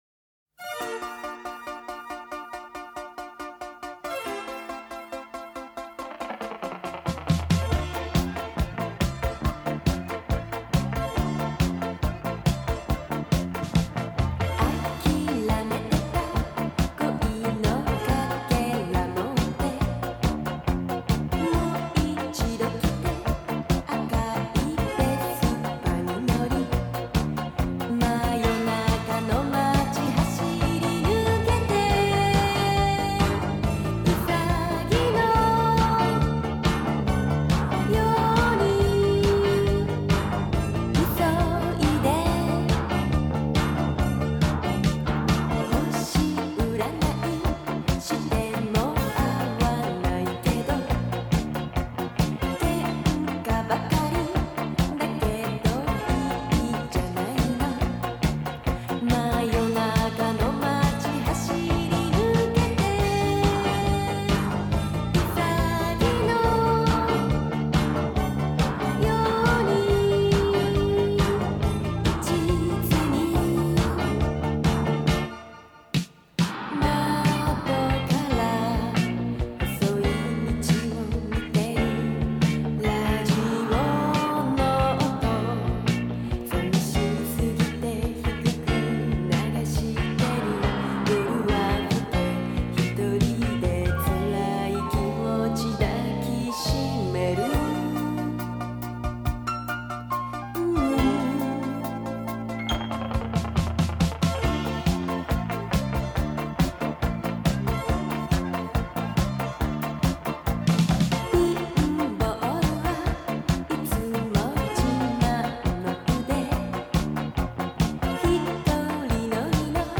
"Bound By Books" has been broadcasting on WGXC since February 2011.